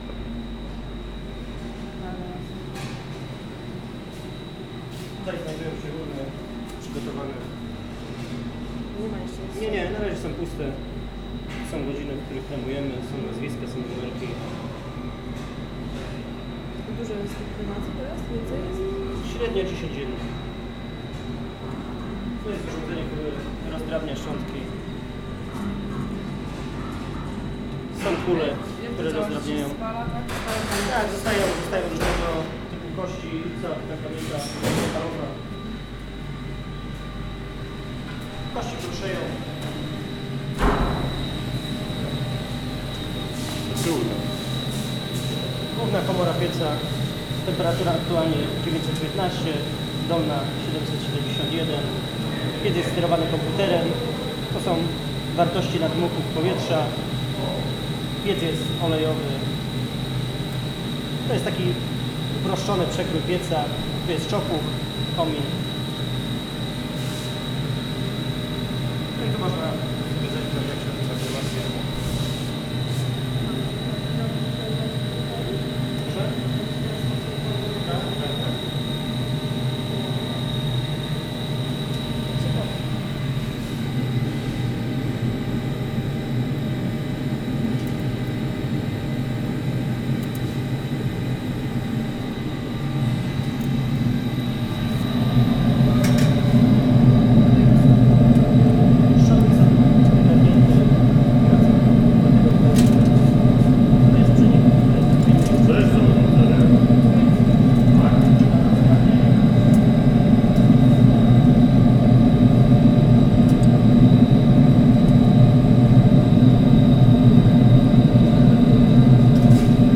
The recording was made in a Crematory in Poznan (Poland) in Ferbruary 2012.
This initiated a public discussion in Polish media about what can be done with our bodies after death and it’s social, political, economical and religious aspects. I went to record the oil runned cremator at work.